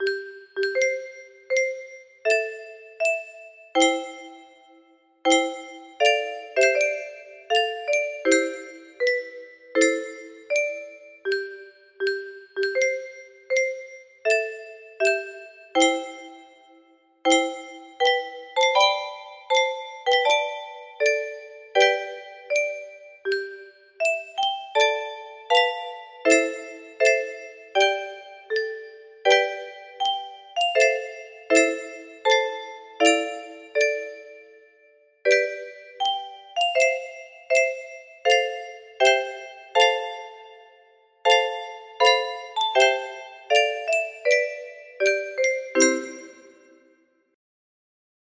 Die Stem 3 music box melody
Imported from Die Stem 31 bars in Dmajorplus adjusted.mid